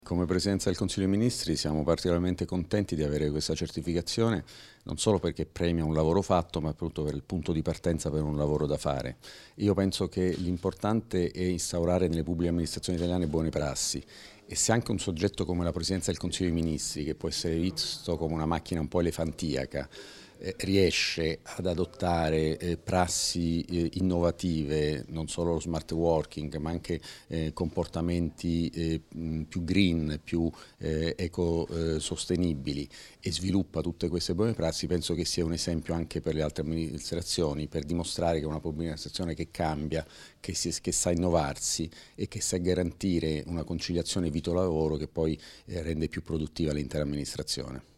INT_ROBERTO_CHIEPPA.mp3